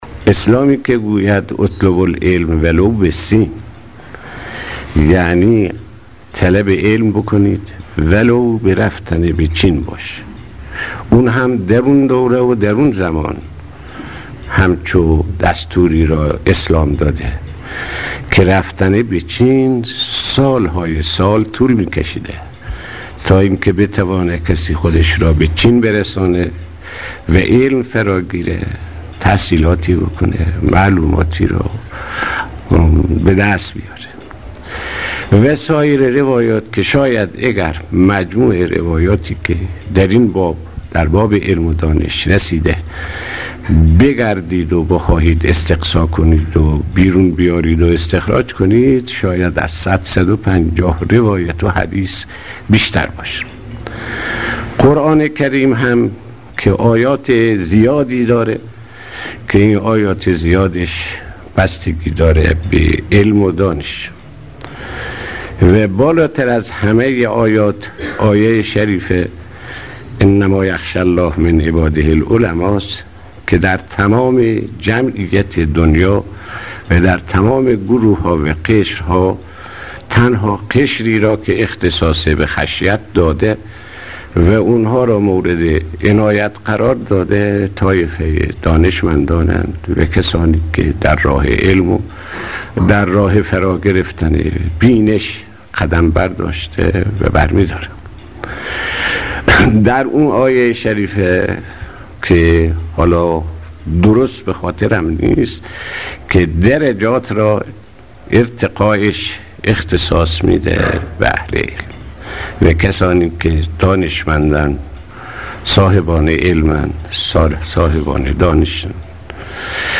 صوت/ سخنرانی شهيد آيت الله صدوقى پیرامون لزوم کسب علم و تاکید اسلام بر آن